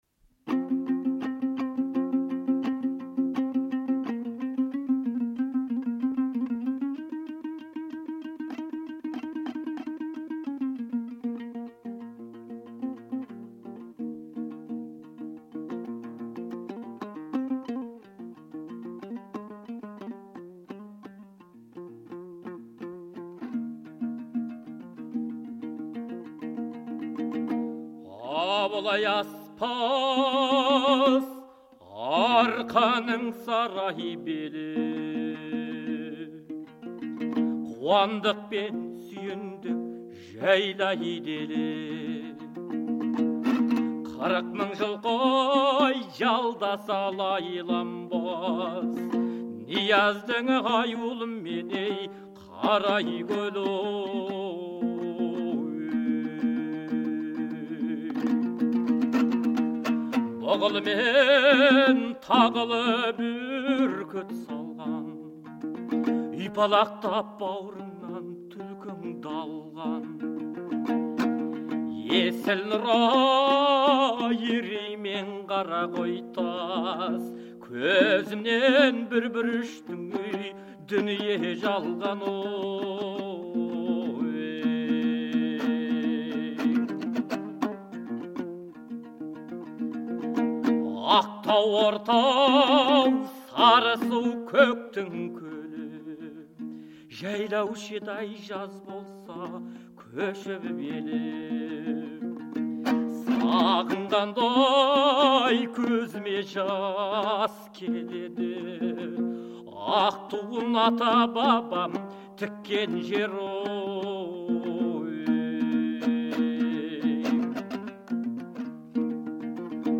Арқа дәстүріндегі қарсылық әндері
Иманжүсіптің әні. Орындаушы - Қайрат Байбосынов